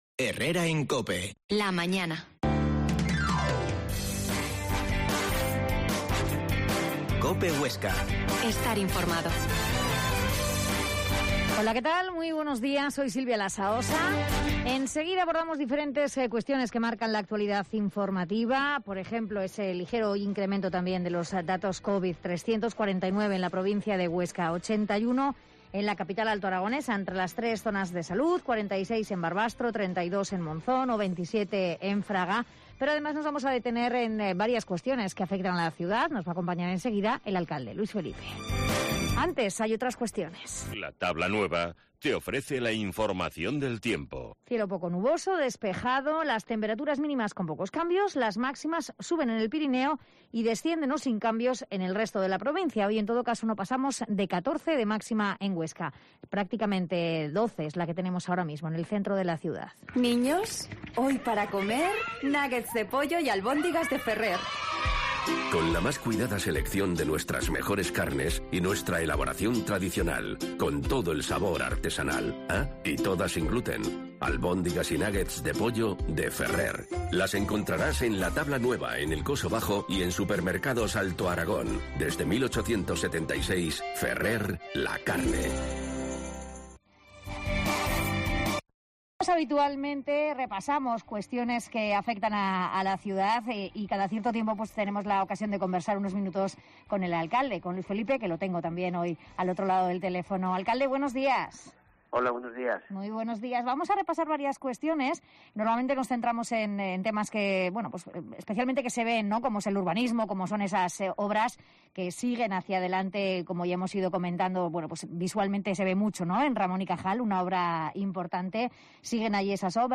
Herrera en Cope Huesca 12,50h. Entrevista al alcalde de Huesca, Luis Felipe
La Mañana en COPE Huesca - Informativo local Herrera en Cope Huesca 12,50h.